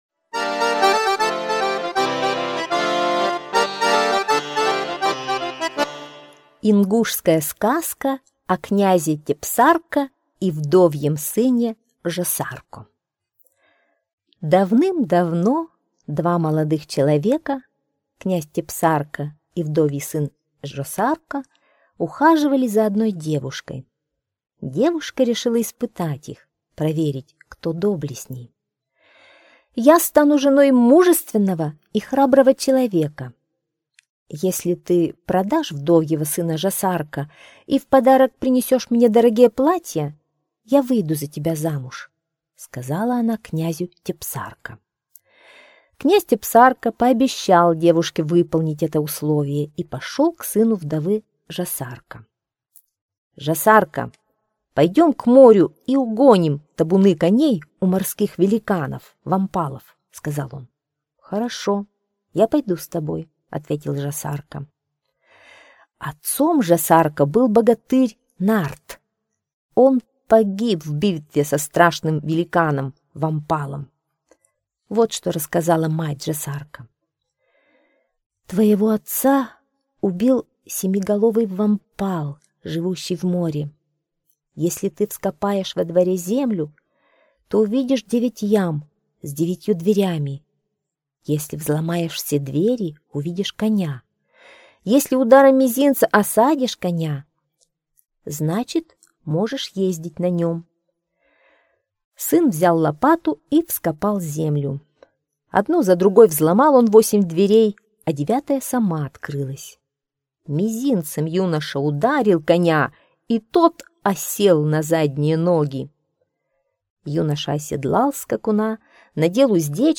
Сказка о князе Тепсарко и вдовьем сыне Жосарко - ингушская аудиосказка.